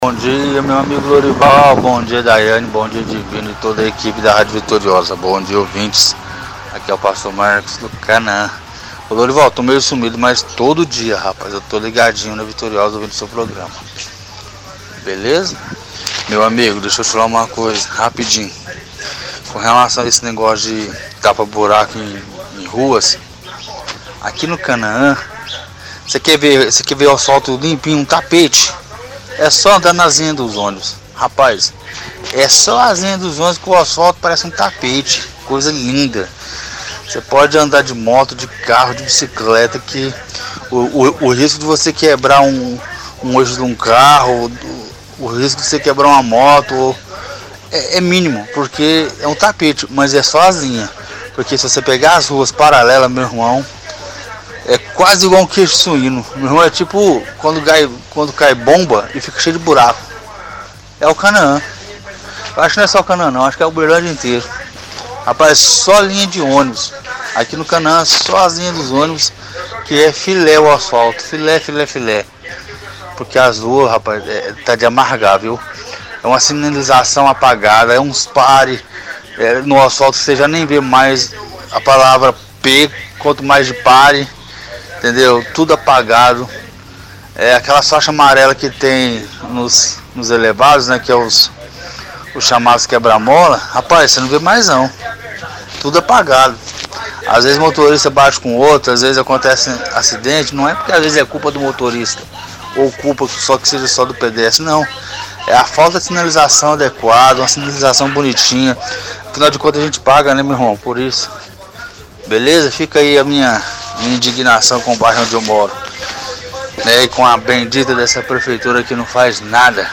-Ouvinte diz que linha do ônibus no bairro Canaã parece um tapete, mas que ruas paralelas parece um queijo suíço todas cheias de buracos.
-Ouvinte reclama de sinalizações apagadas.